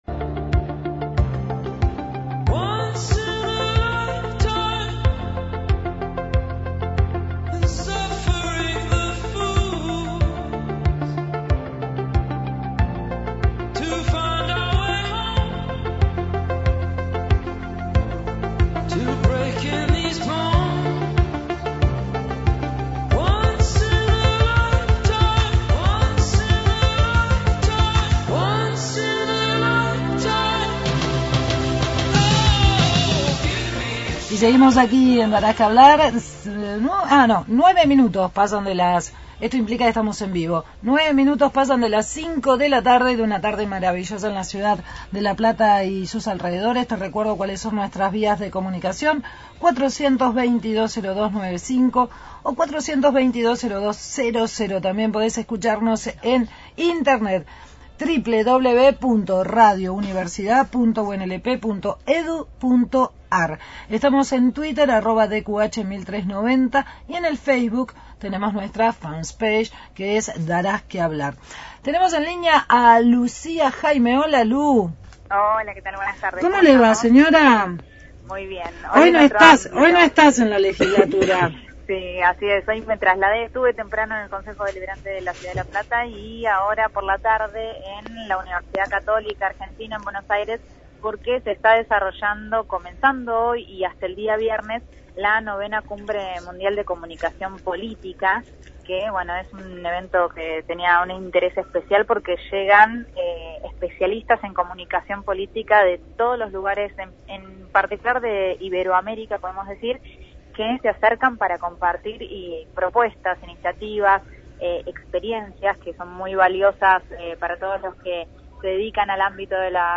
desde IX Cumbre Mundial de Comunicación Política